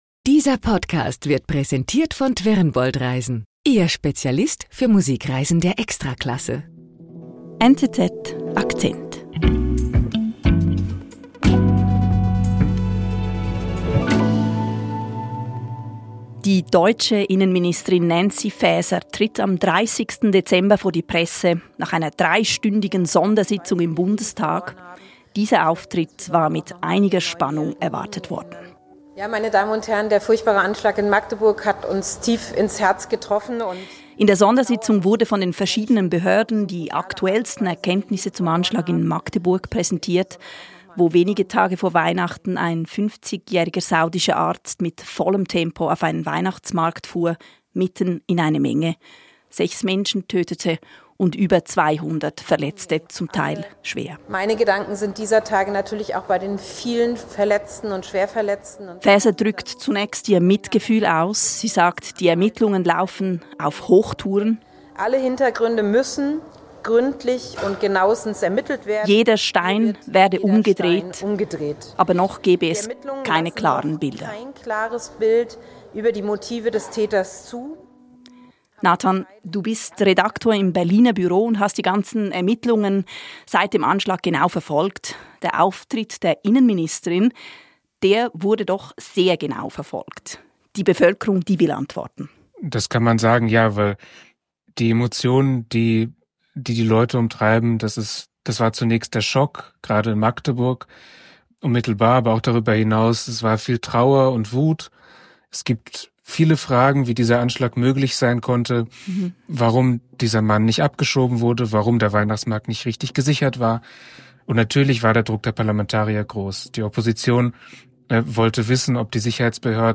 Täglich erzählen NZZ-Korrespondentinnen und Redaktoren, was sie bewegt: Geschichten aus der ganzen Welt inklusive fundierter Analyse aus dem Hause NZZ, in rund 15 Minuten erzählt.